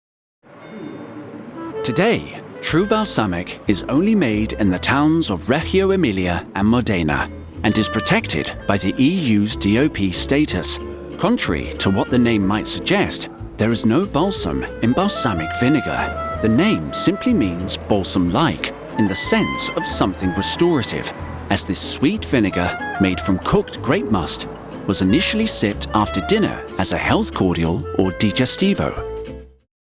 Audio Guides
Recording booth-Session Booth custom-built air-conditioned studio
Mic-Neumann TLM 103